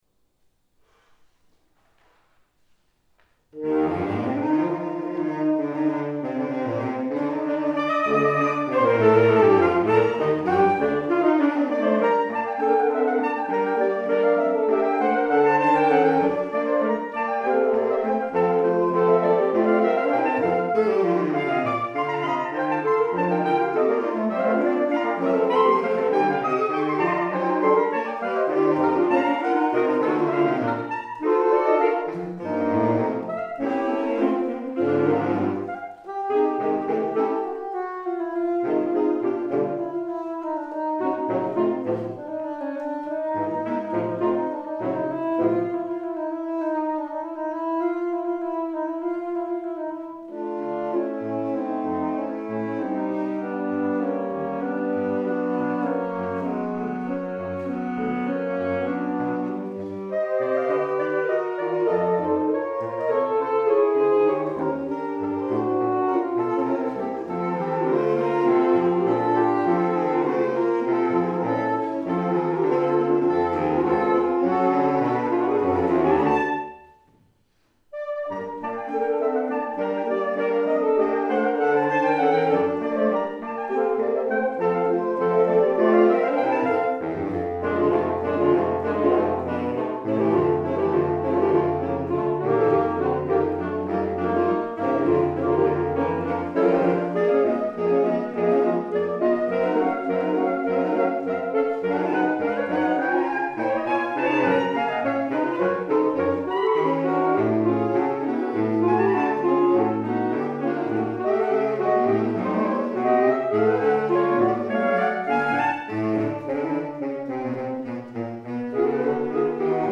[recorded live at Plattsburgh State University, 2 April 2016]